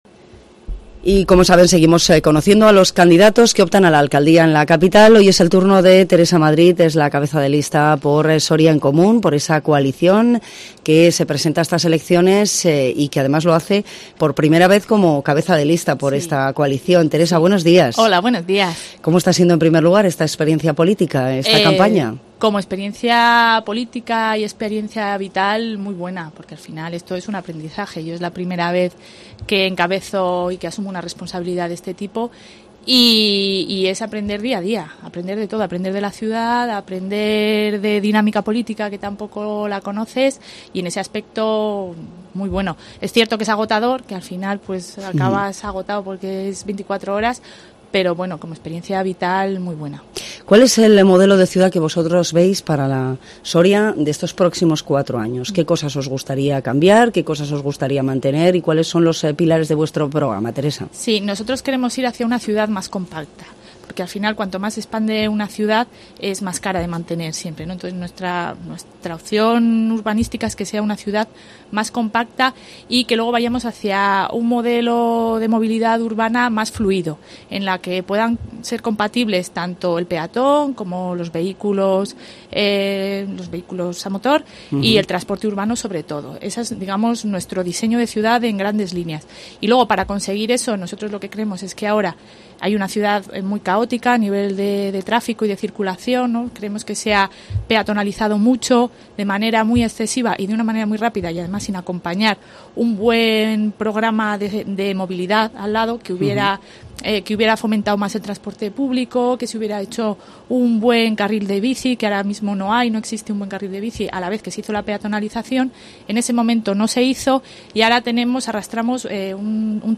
Entrevista Soria en Común elecciones municipales Soria